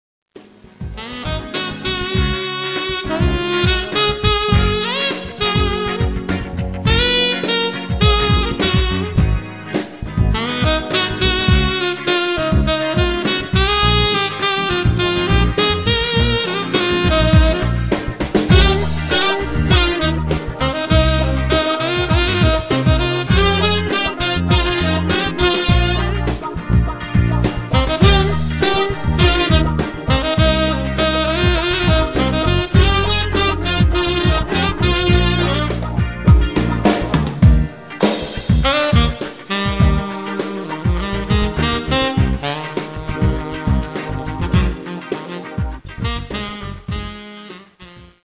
Recorded at Sanctuary Studios, Broadalbin, NY 2004-2011